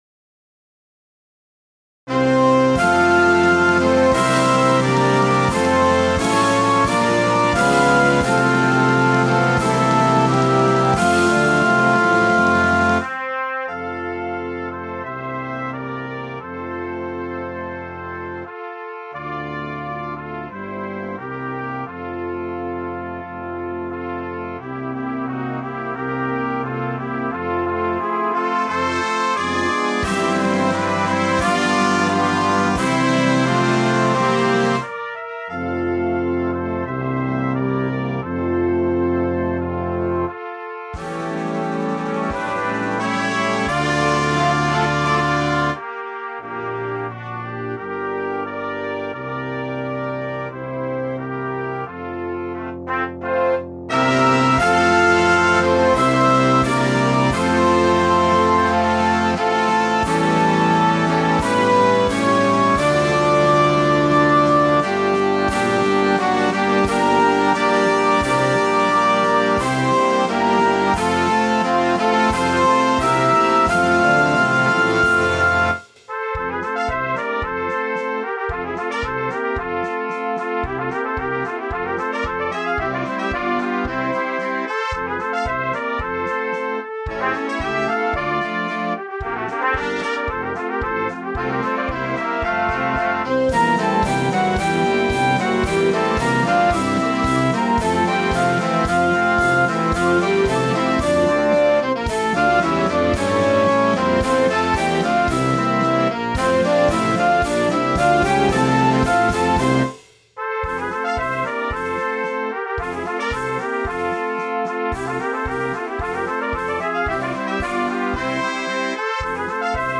Besetzung: 2 Flügelhörner Solo & Blasorchester